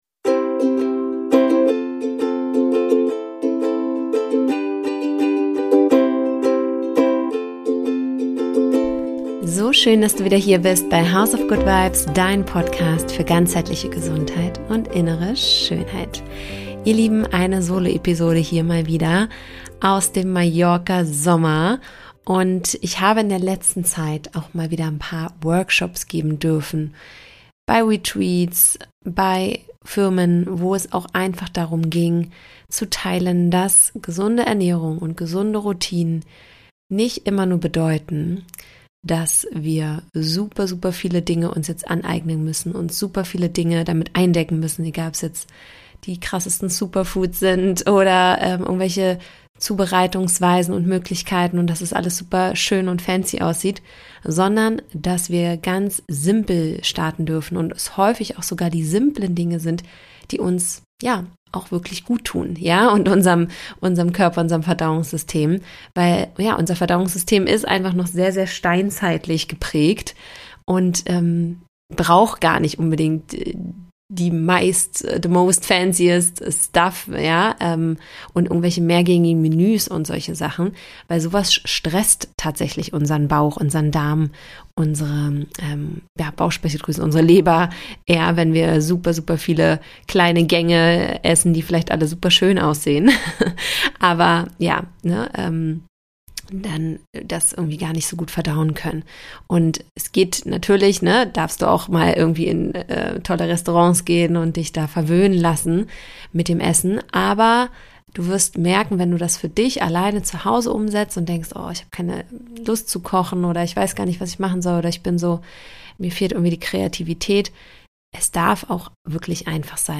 In dieser kurzen, ehrlichen Solo-Folge geht’s um das, was oft untergeht: Gesunde Ernährung darf einfach sein.